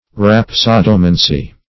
Search Result for " rhapsodomancy" : The Collaborative International Dictionary of English v.0.48: Rhapsodomancy \Rhap"so*do*man`cy\, n. [Rhapsody + -mancy.] Divination by means of verses.